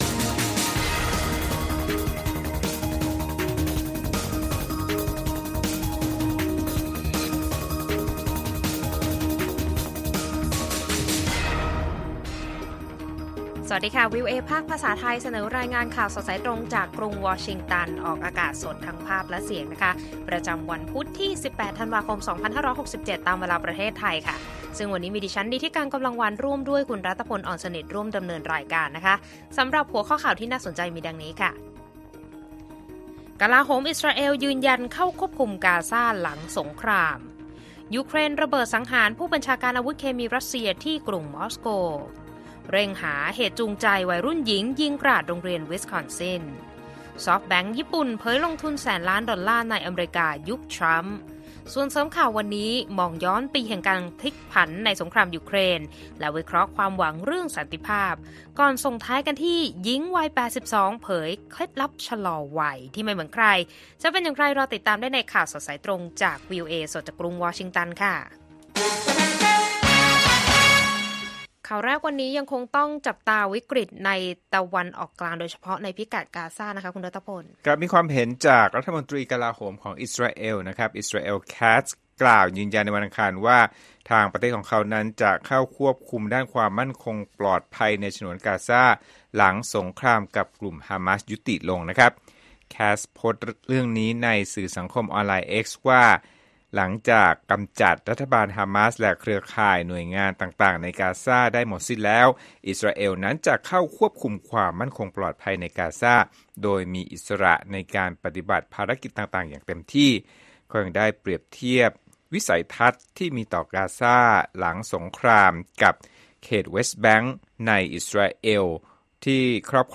ข่าวสดสายตรงจากวีโอเอไทย วันพุธ ที่ 18 ธ.ค. 67